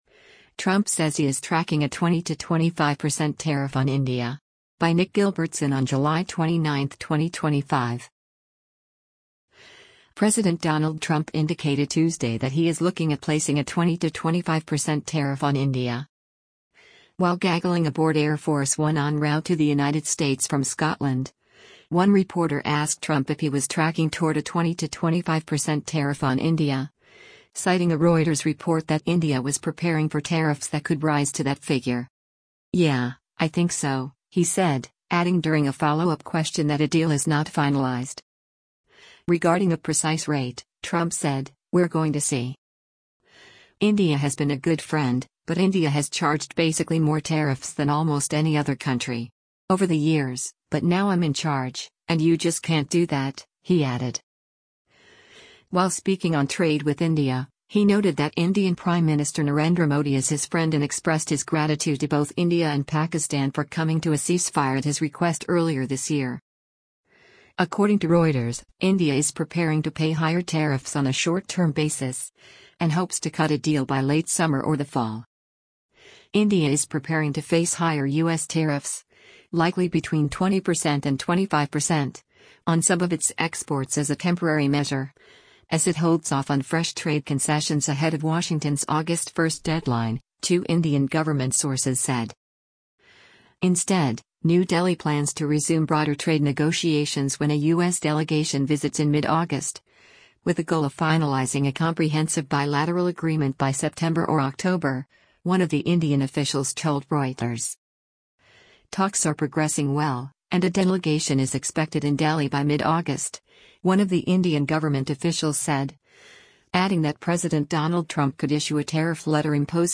While gaggling aboard Air Force One en route to the United States from Scotland, one reporter asked Trump if he was “tracking” toward a 20-25 percent tariff on India, citing a Reuters report that India was preparing for tariffs that could rise to that figure.